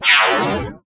SCIFI_Sweep_03_mono.wav